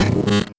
ElectricTransform.wav